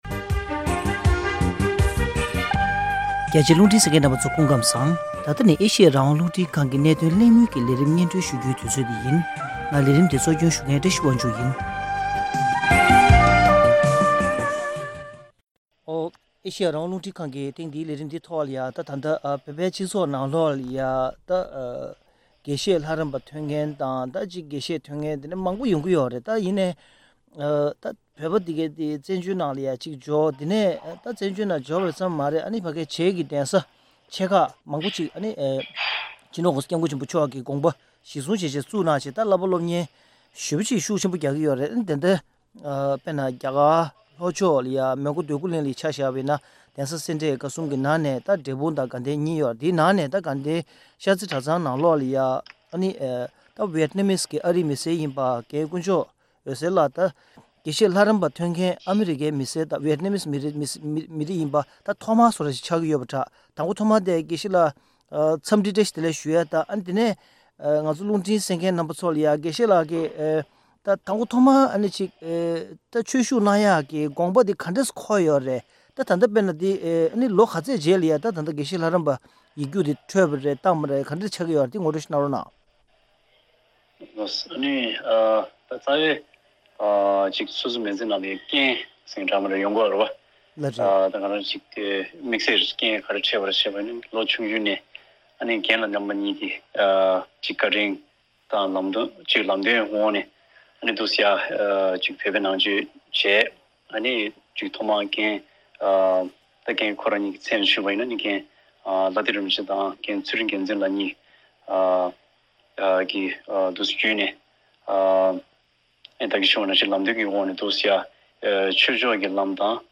ཁོང་གི་མི་ཚེ་ནང་བླ་ཏི་རིན་པོ་ཆེས་ཤུགས་རྐྱེན་ཆེན་པོ་བྱུང་ཡོད་པ་ཞིག་ཡིན་འདུག ཐེངས་འདིའི་གནད་དོན་གླེང་མོལ་གྱི་ལས་རིམ་ནང་།